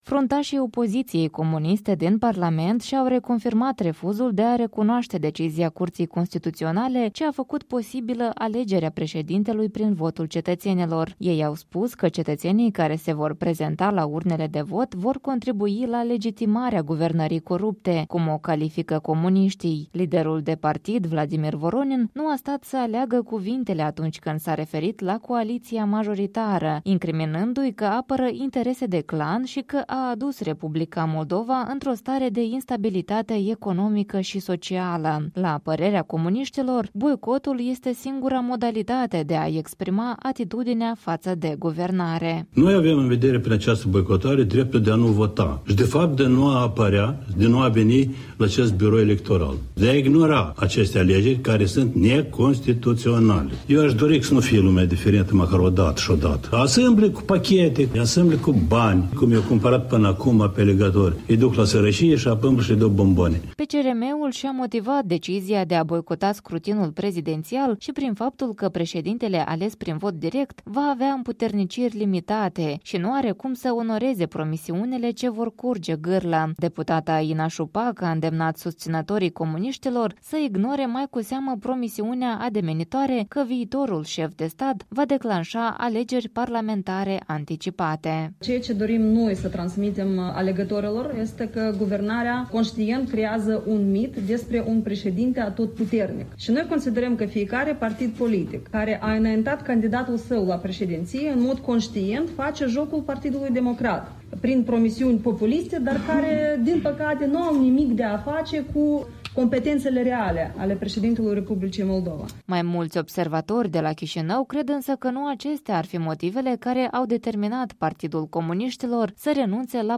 Astăzi, la o conferință de presă, liderul PCRM, fostul președinte al țării Vladimir Voronin, a îndemnat la boicotarea scrutinului, pe care formațiunea sa îl consideră ilegal.